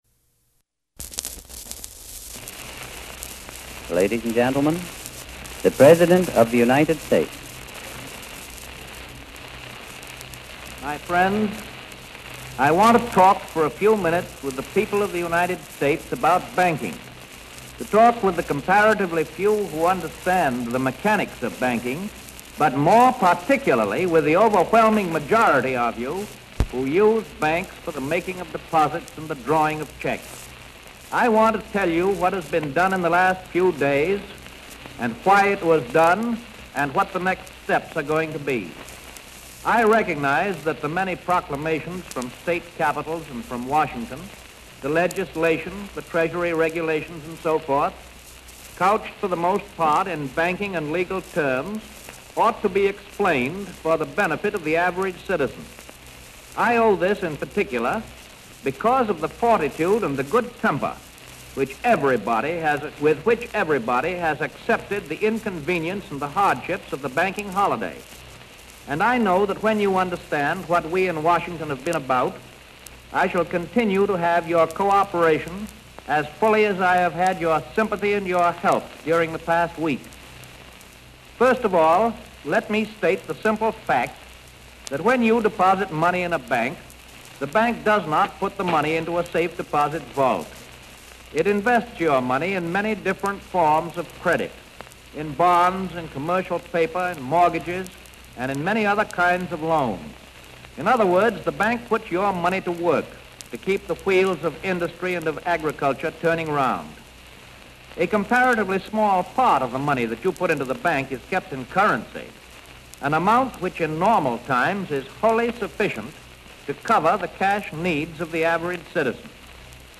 Listen to Roosevelt's Fireside Chat on the banking crisis which he recorded on March 12, 1933.
mod7top4_content_firesideaudio.mp3